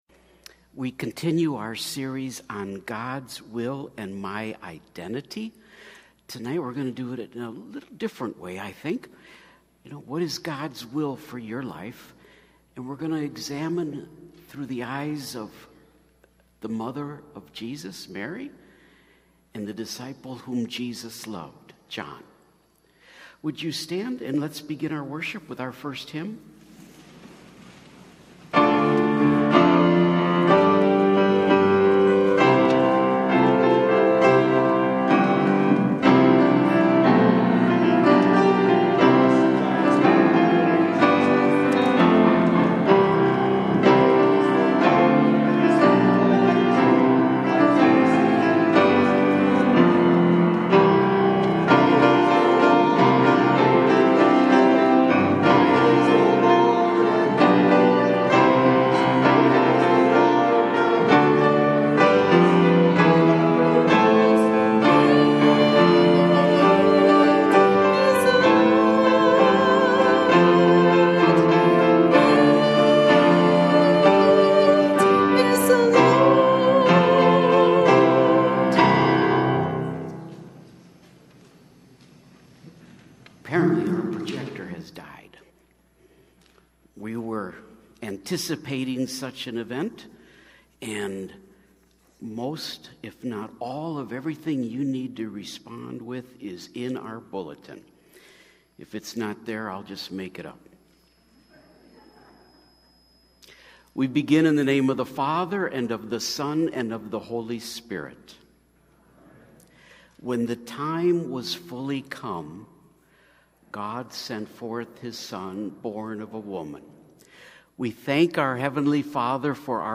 Mar 3 / Sat Blended – Relating to Mary – Lutheran Worship audio